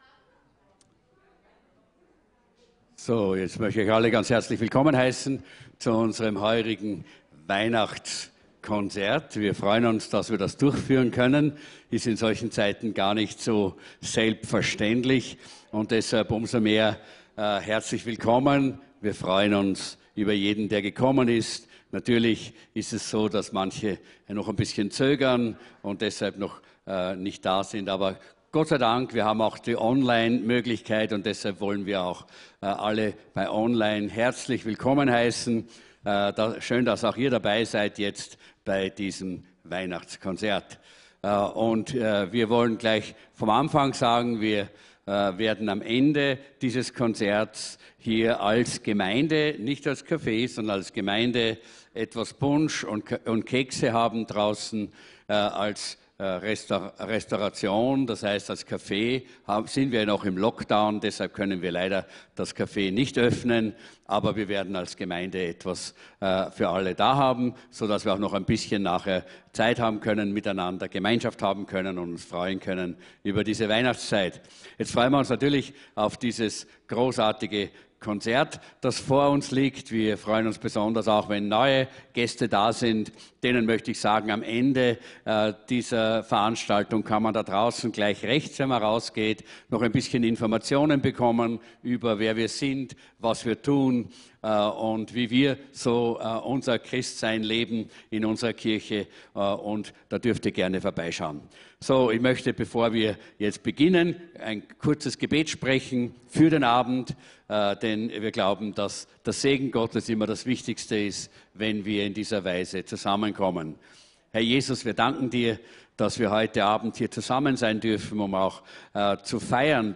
Weihnachtskonzert 2021